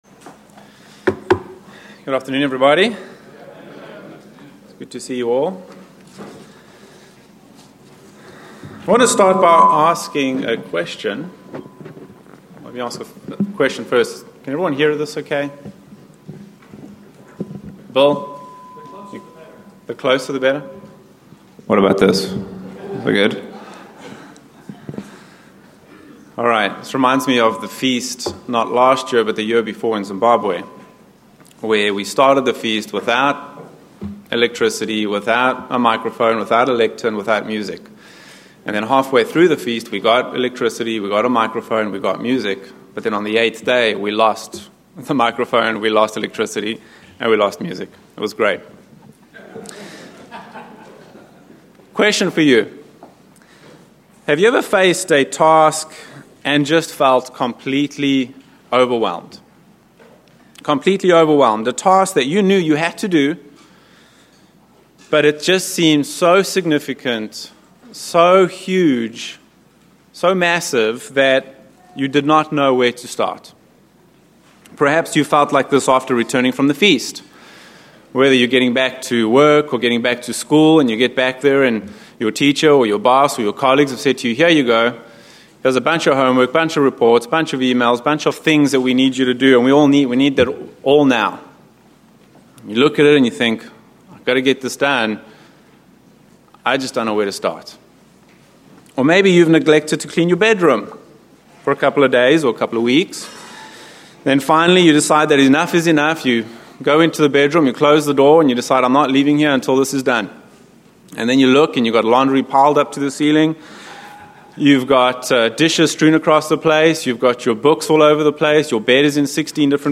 Given in Dallas, TX